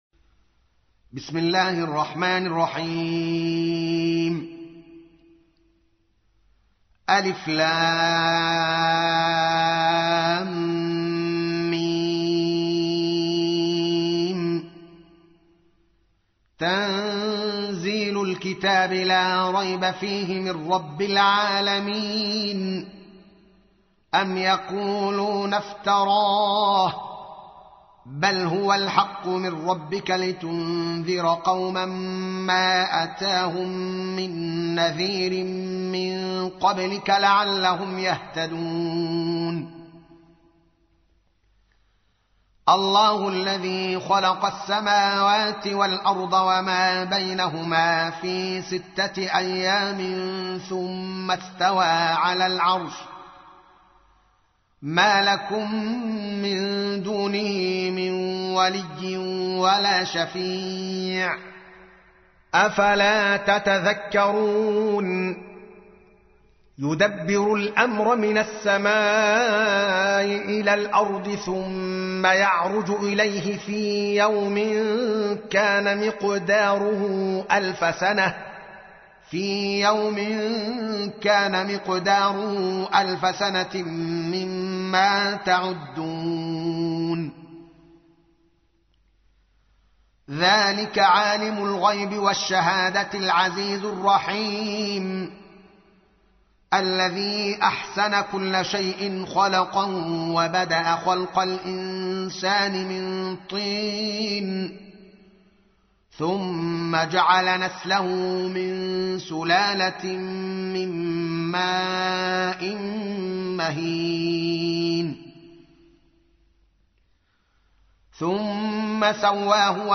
تحميل : 32. سورة السجدة / القارئ الدوكالي محمد العالم / القرآن الكريم / موقع يا حسين